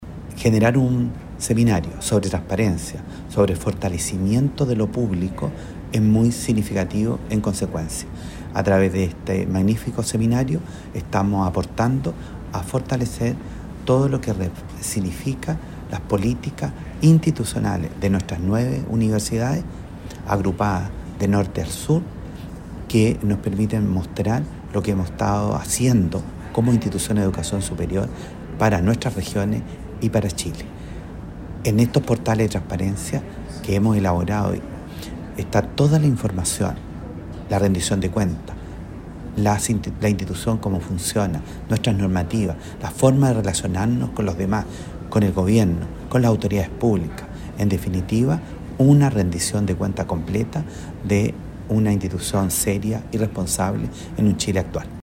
Con una fuerte apuesta por el fortalecimiento de la confianza pública y el acceso abierto a la información, las universidades agrupadas en la Red G9 celebraron el pasado viernes 28 de marzo el seminario “Transparencia en Educación Superior: avances y desafíos” en la Unidad de Santiago de la Universidad de Concepción (UdeC).